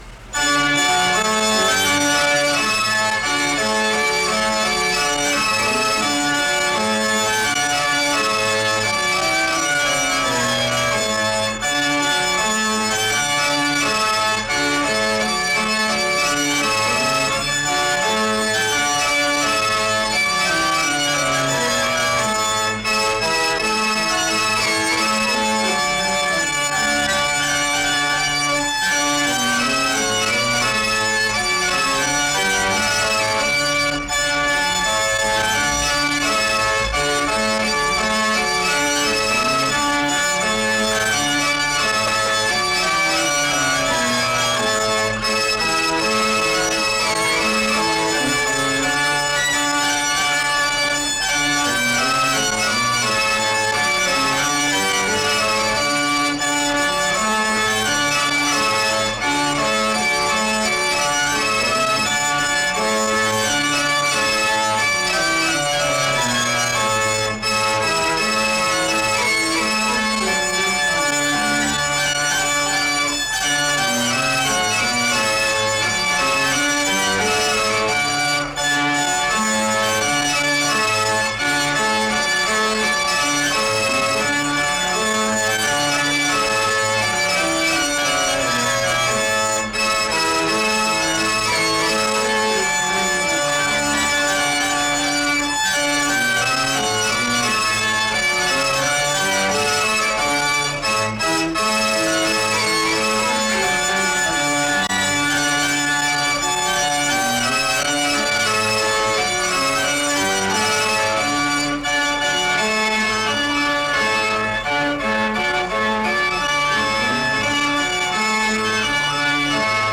SEBTS Commencement
SEBTS Chapel and Special Event Recordings